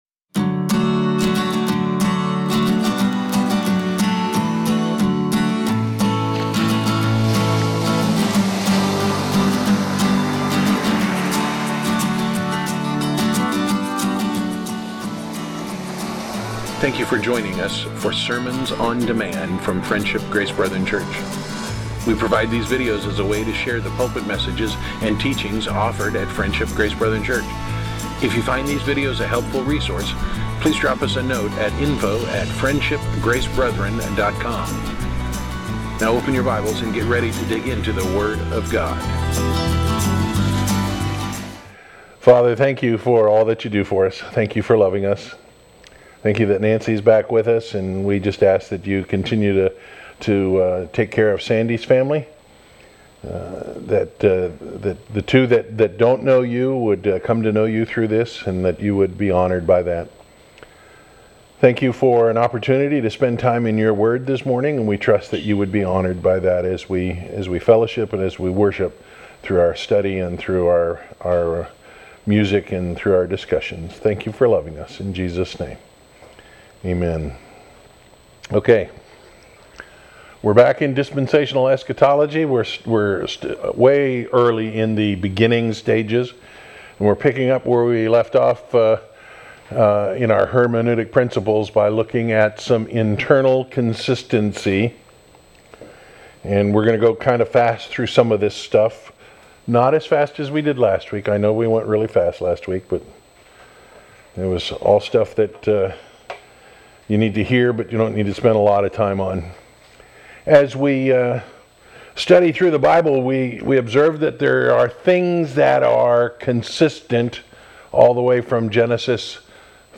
Series: Dispensational Eschatology, Sunday School